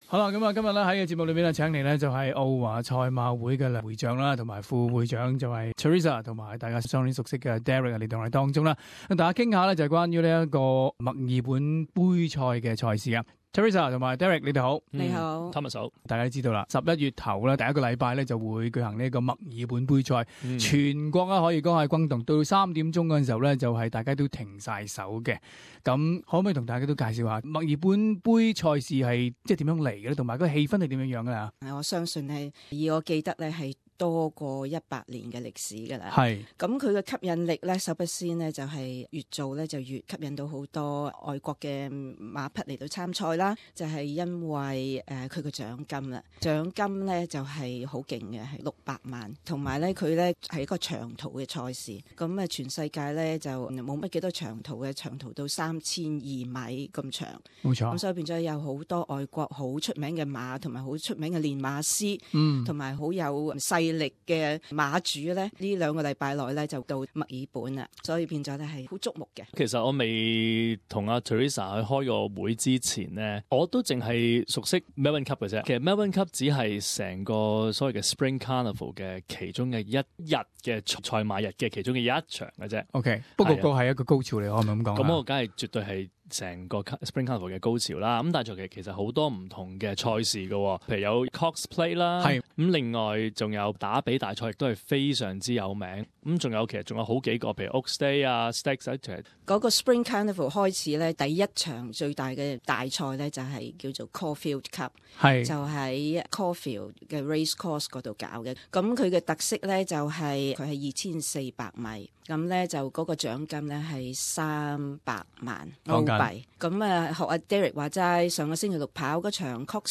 【社區專訪】今屆墨爾本杯賽終於上場